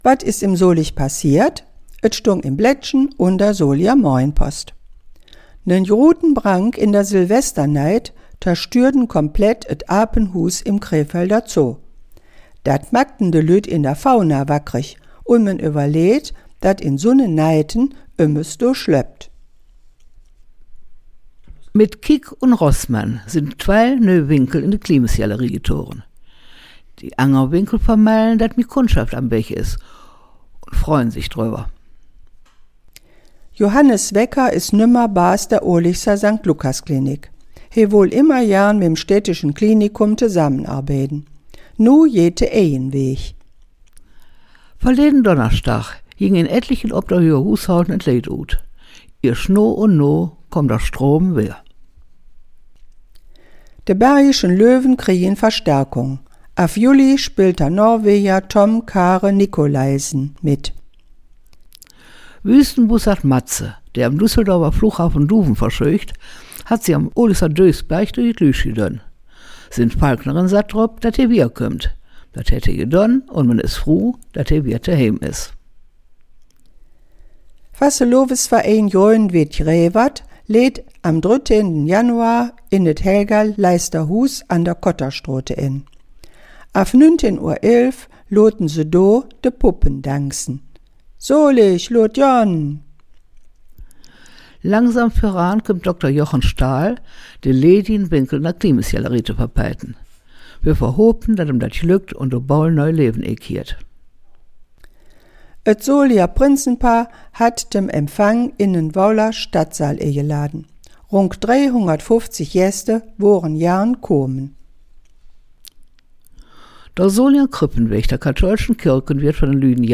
Nachrichten in Solinger Platt – aktuelle Mundart
Solinger-Platt-News-20kw02.mp3